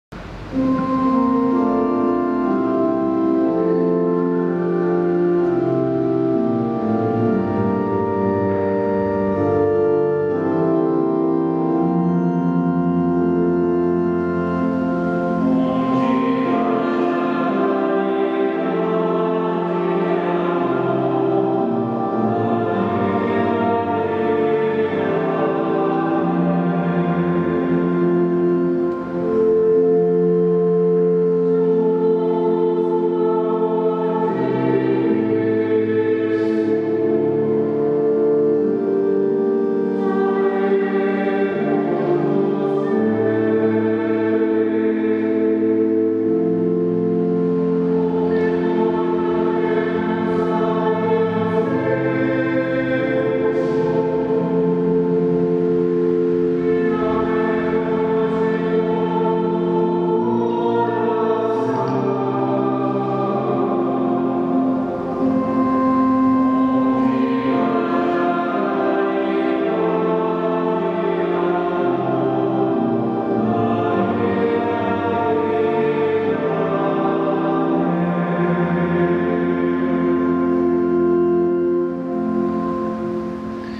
CANTO DE MEDITAÇÃO Se houver a verdadeira caridade os cristãos estão aptos a ultrapassar todos os problemas https